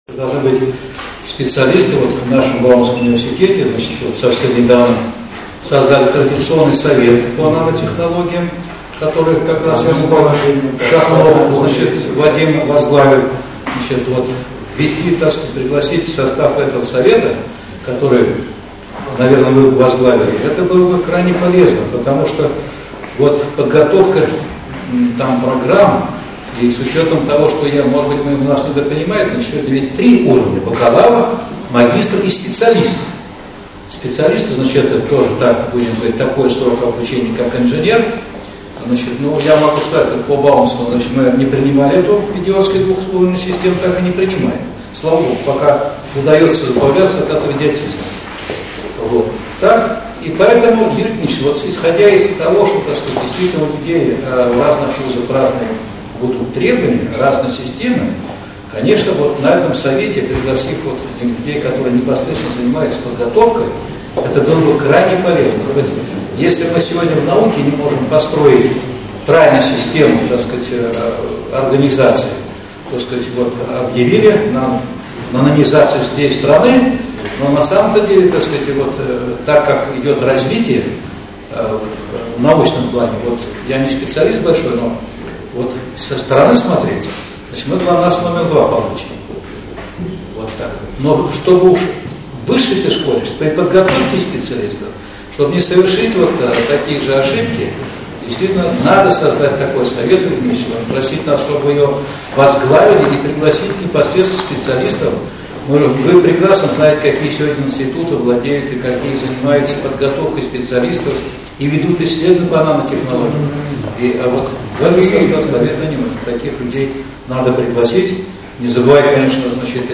Аудиозапись выступлений